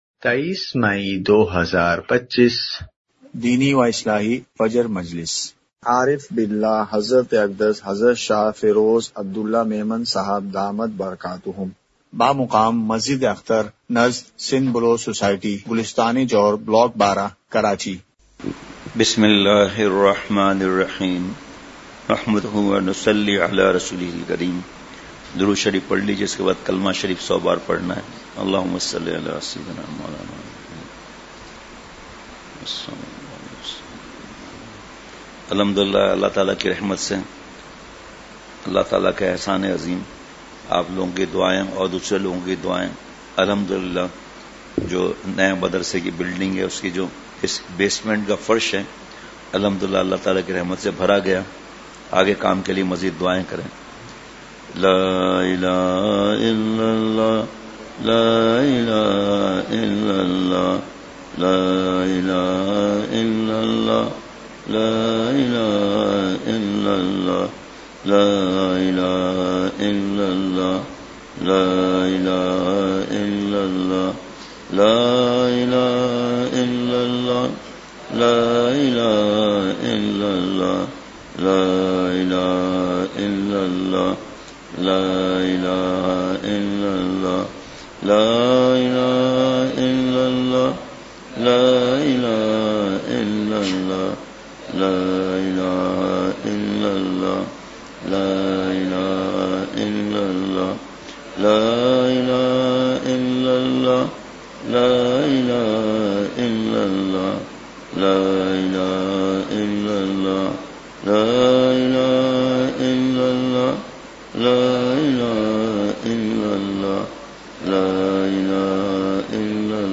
مقام:مسجد اختر نزد سندھ بلوچ سوسائٹی گلستانِ جوہر کراچی
مجلسِ ذکر!کلمہ طیّبہ۔۔۔